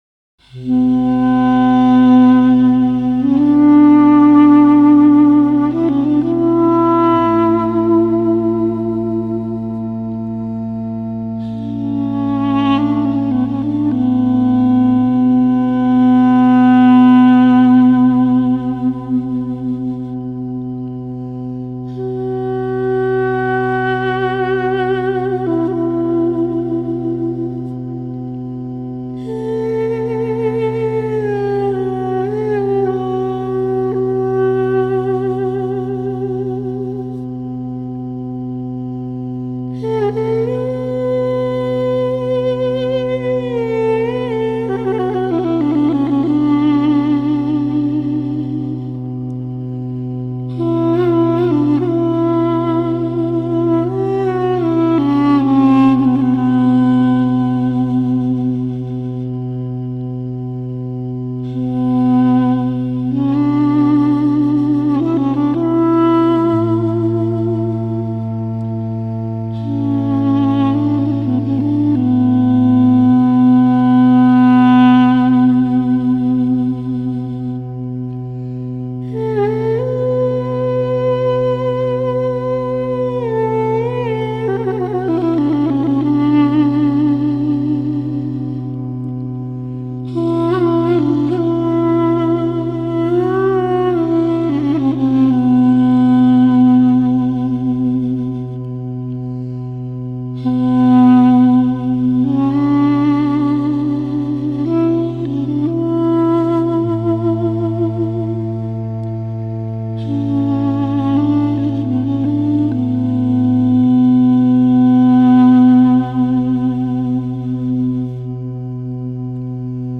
The warm, nasal tones of the duduk played by